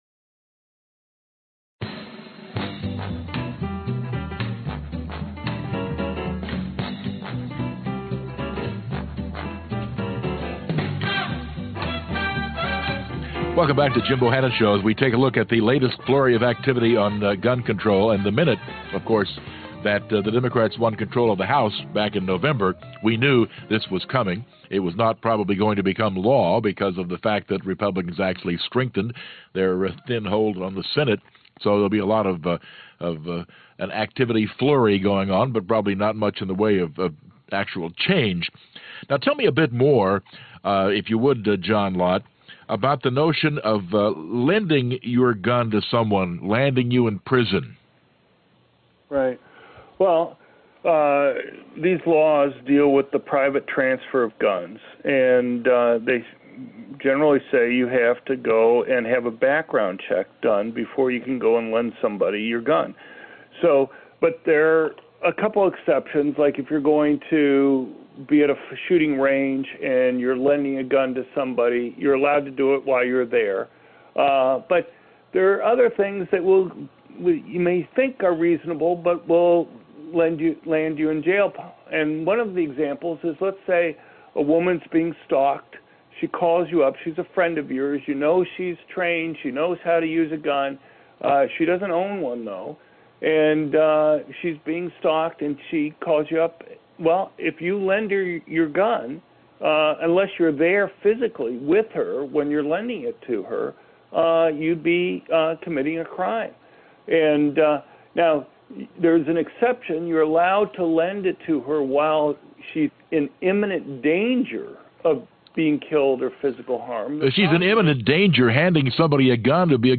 Dr. John Lott talked to Jim Bohannon on his national radio show about the background check bills passed by the US House. Lott than took questions from the audience for over an hour about the gun control debate.